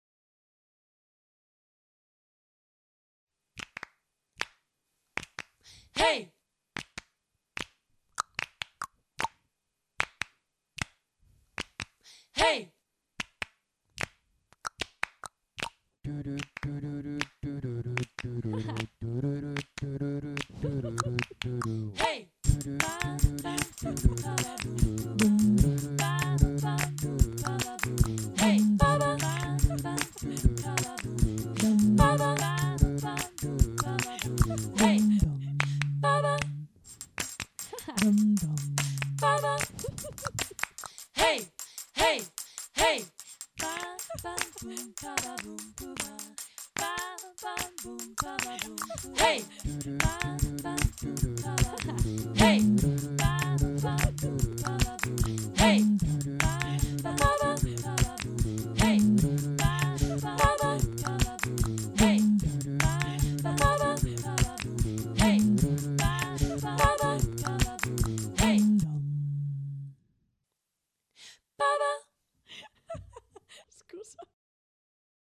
Music Track